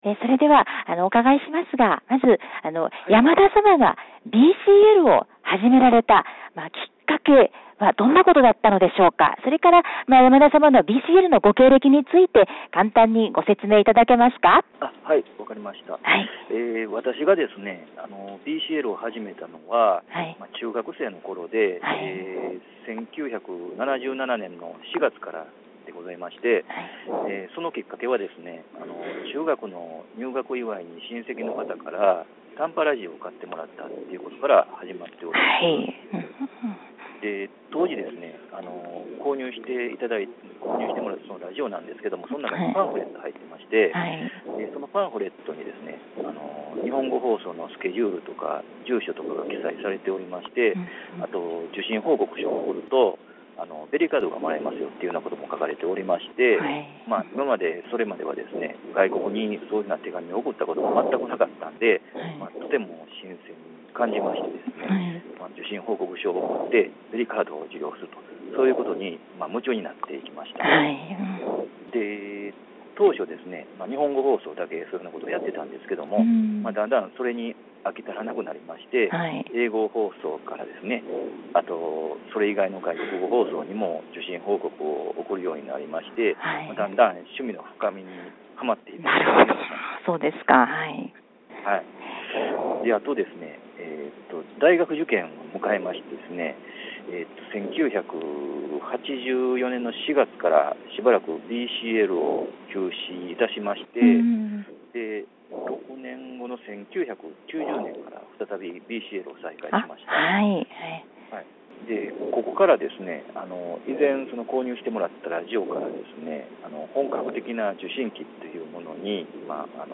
金曜広場 インタビュー